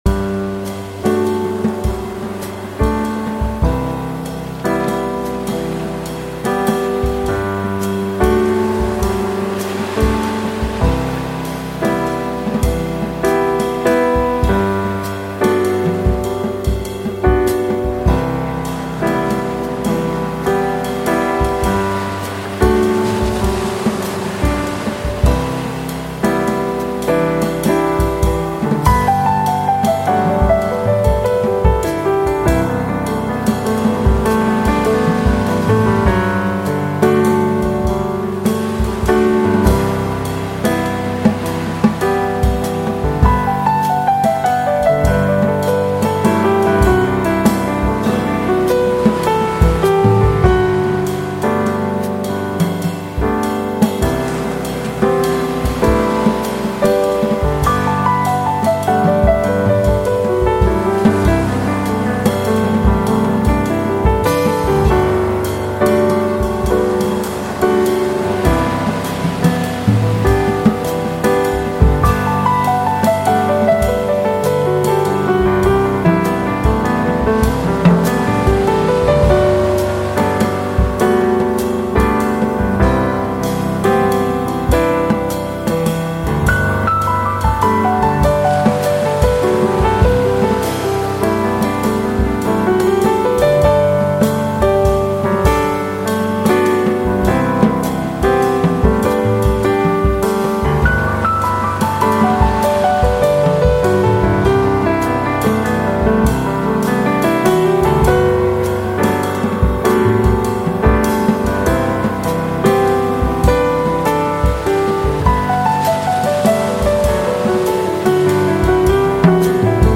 Jazz & Blues Music